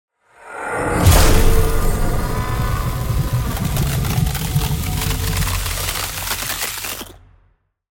SFX魔法攻击的冰枪声音效下载
SFX音效